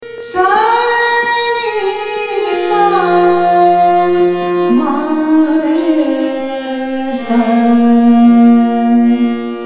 ThaatKafi
AvarohaS’ n P m R S